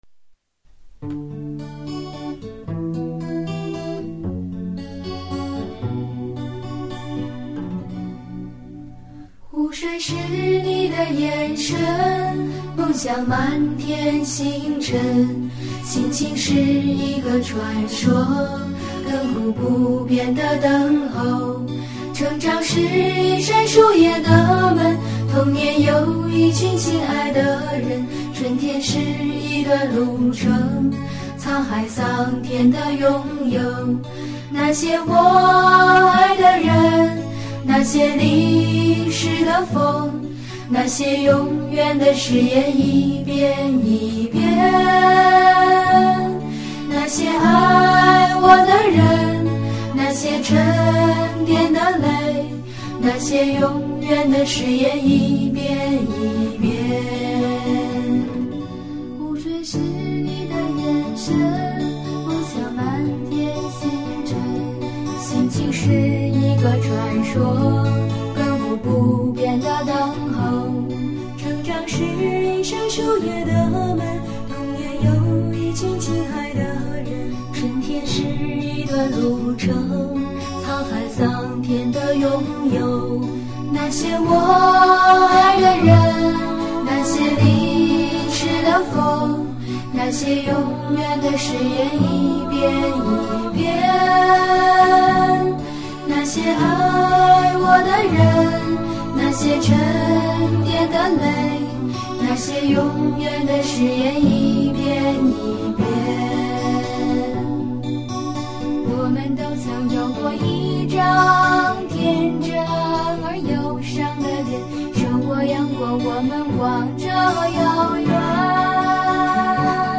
副歌独唱
副歌伴唱
1998年冬，在中央民族大学一间教室里演唱